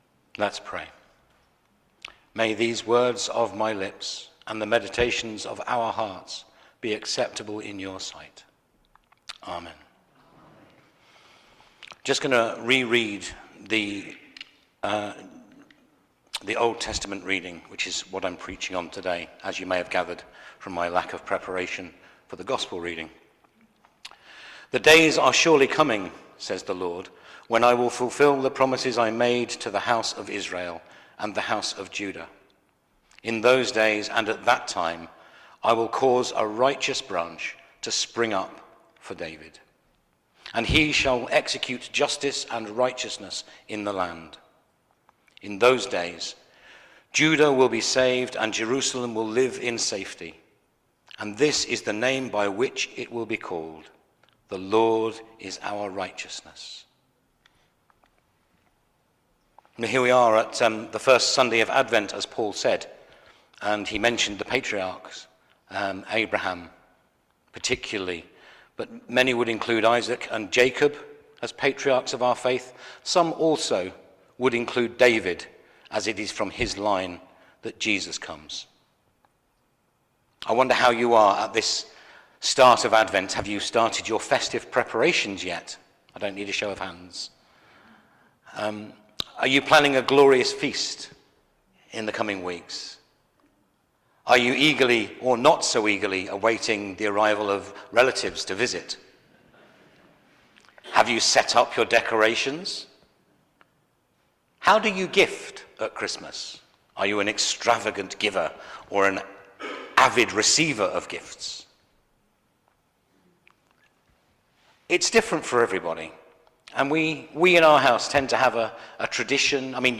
Media for Holy Communion on Sun 01st Dec 2024 09:00 Speaker
Theme: Future restoration in times of despair Sermon Search